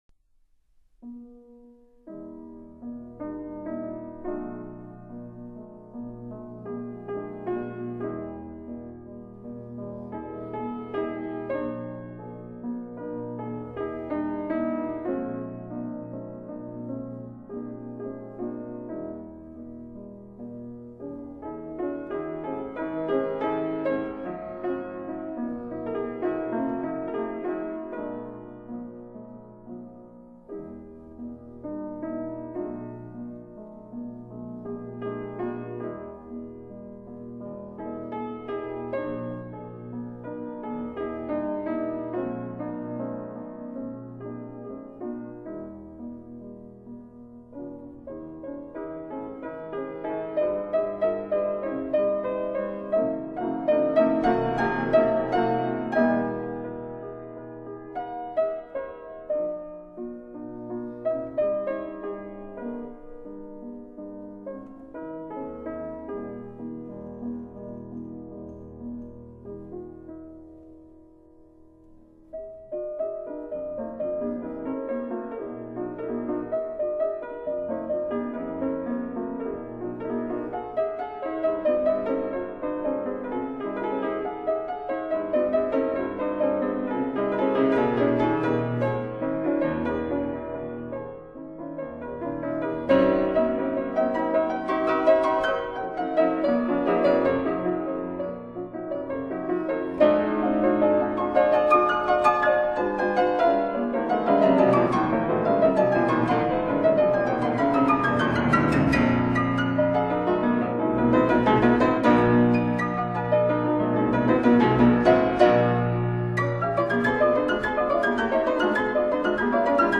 其錄音溫暖的音色令十九世紀的音響世界歷歷在目，由於任何其他所謂的「古樂器」錄音。
使用樂器：Erard piano from 1849